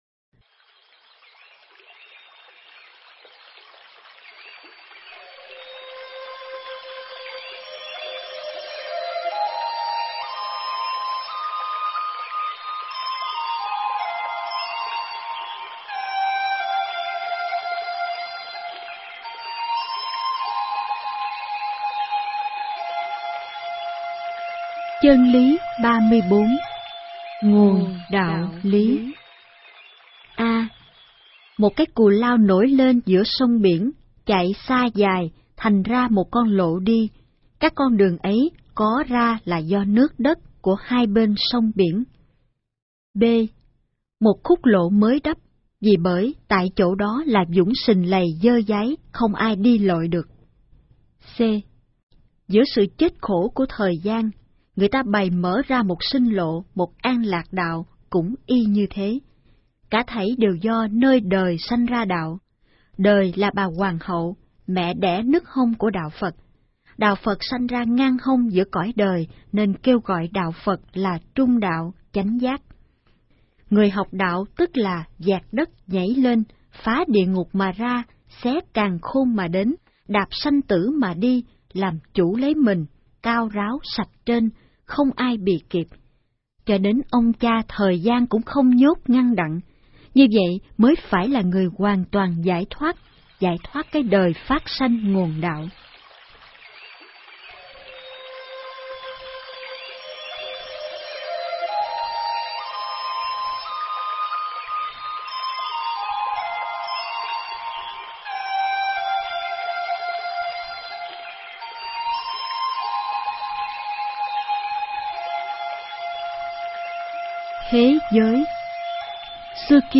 Nghe sách nói chương 34.